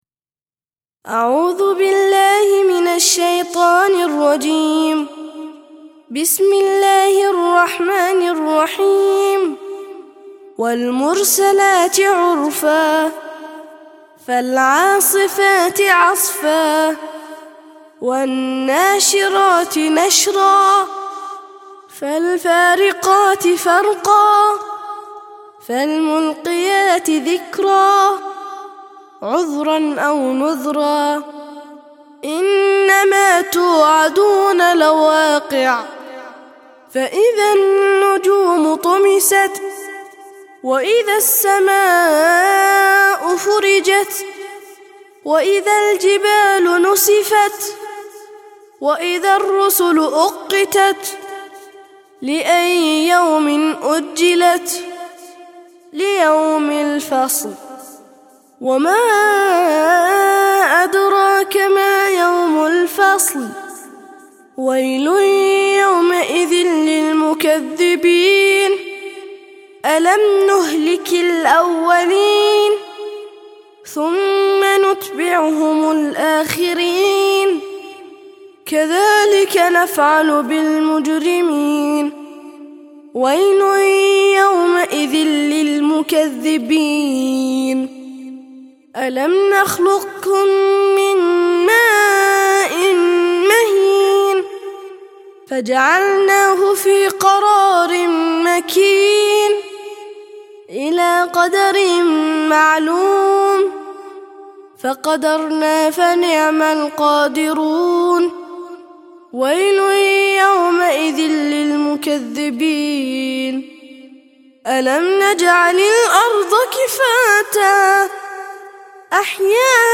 77- سورة المرسلات - ترتيل سورة المرسلات للأطفال لحفظ الملف في مجلد خاص اضغط بالزر الأيمن هنا ثم اختر (حفظ الهدف باسم - Save Target As) واختر المكان المناسب